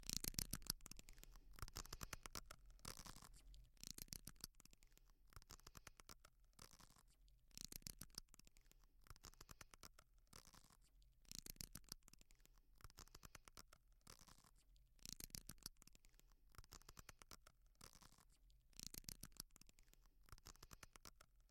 描述：Tirantes estirados。 Grabado con grabadora zoomH4n
Tag: stretchout